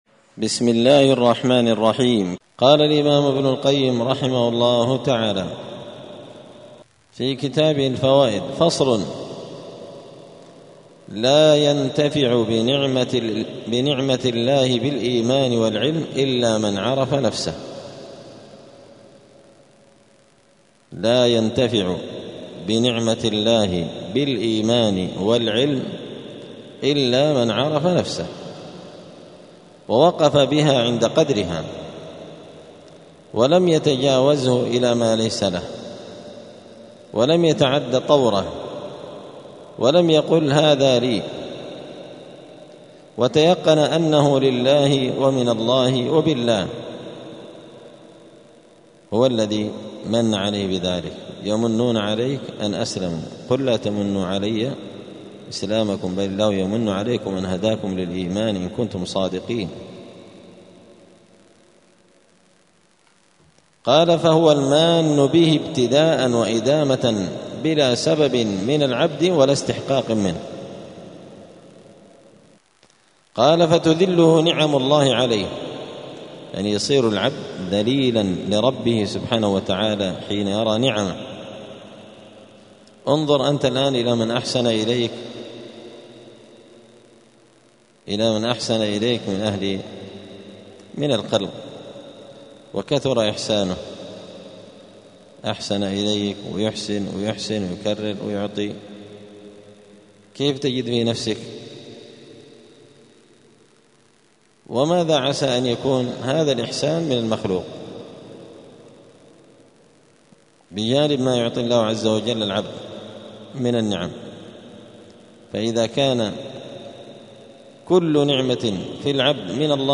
الأحد 11 محرم 1447 هــــ | الدروس، دروس الآداب، كتاب الفوائد للإمام ابن القيم رحمه الله | شارك بتعليقك | 13 المشاهدات
دار الحديث السلفية بمسجد الفرقان قشن المهرة اليمن